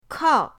kao4.mp3